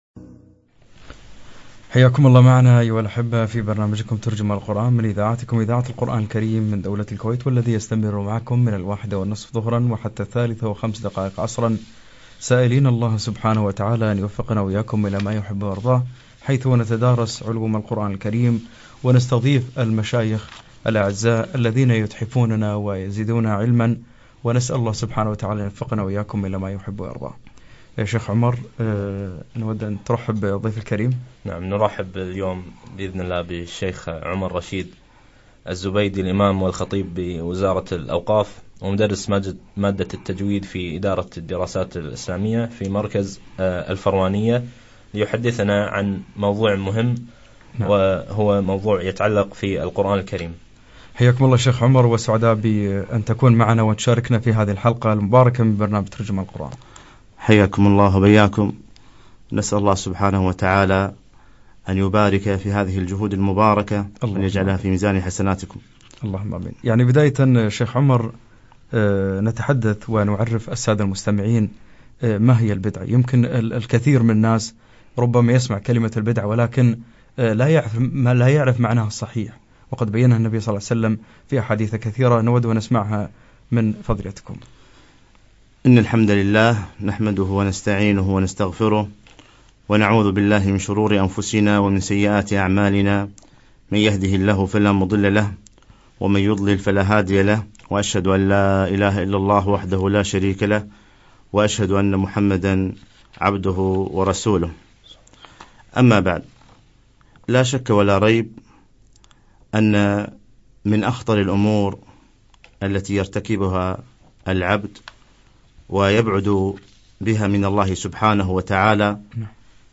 بدع القراء لقاء عبر إذاعة القرآن بدولة الكويت
بدع القراء - لقاء إذاعي